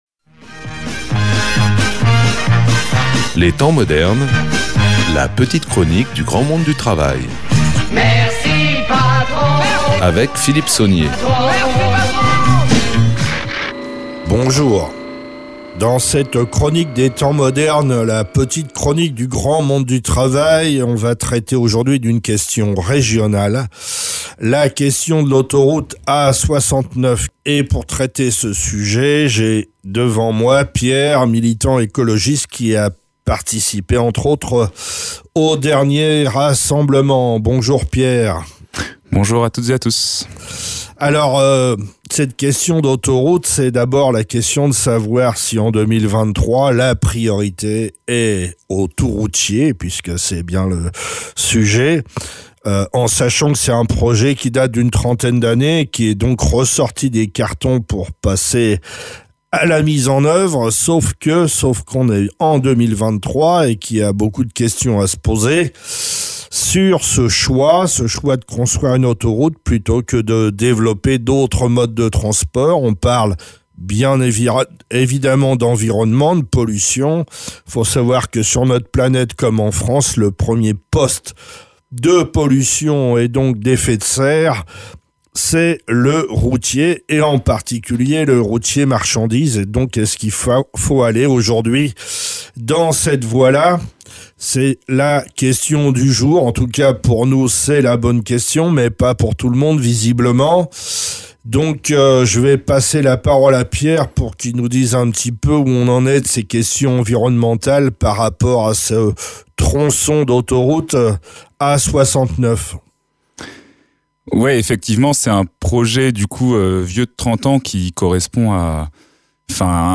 Avec un militant présent lors de la manifestation contre l’autoroute A69, quelques clefs de compréhension.